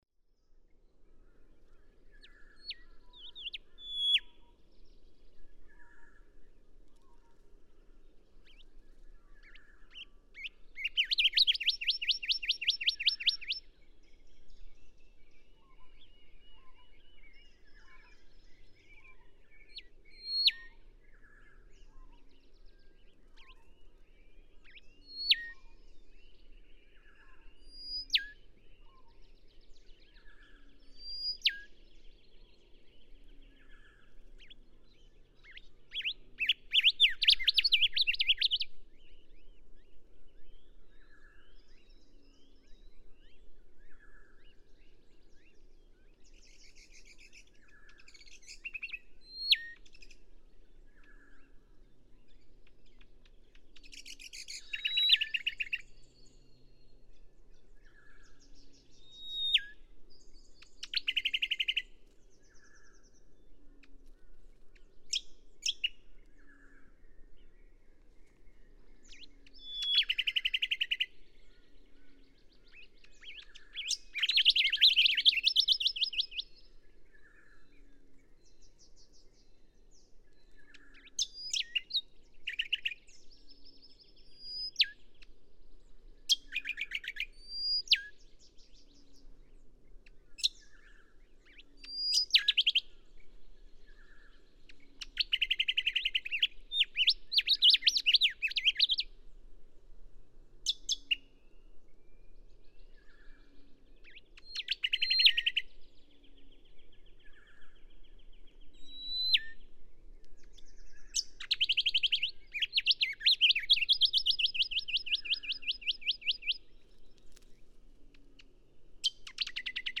Rufous whistler
For the better part of an hour, I stood in awe of this bird, aiming the parabola at him, marveling at all that he sang. I offer here the best 18 minutes, including the 19-second song beginning at about 6:15.
730_Rufous_Whistler.mp3